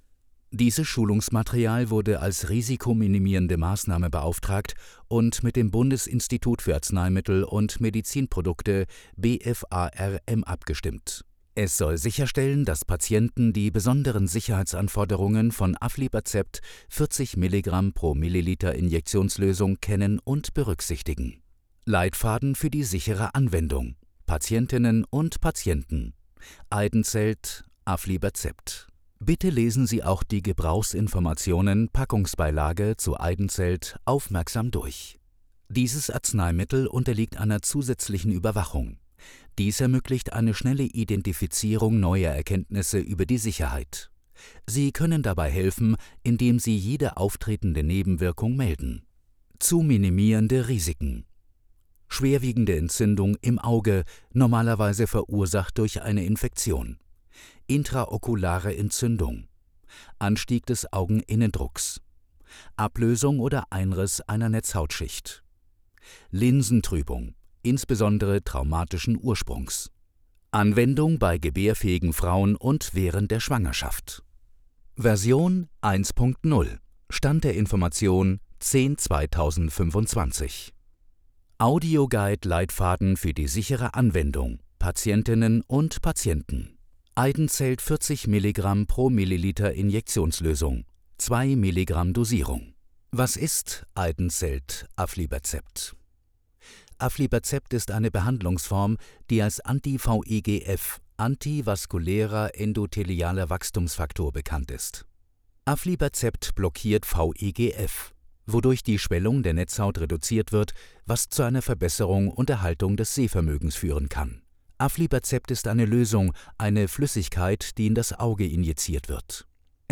Audioguide – Patientinnen und Patienten